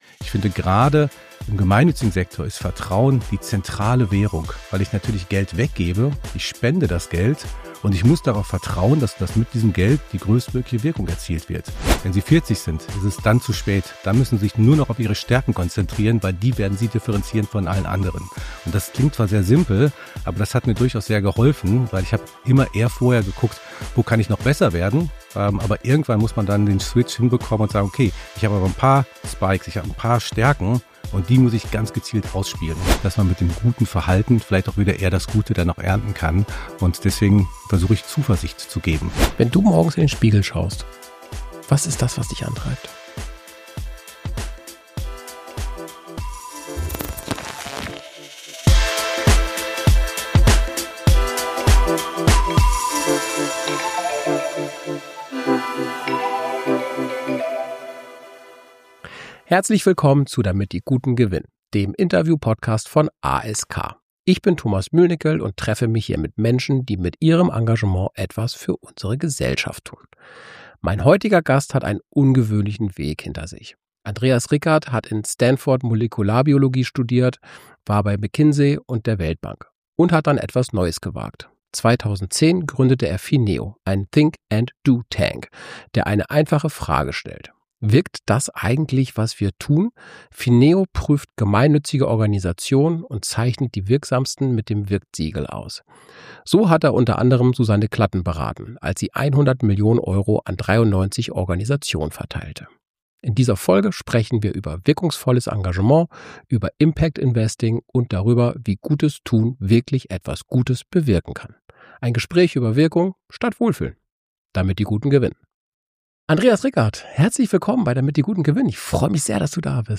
Ein tiefgehendes Gespräch über Verantwortung, Wirkung und die Zukunft des gesellschaftlichen Engagements in Deutschland.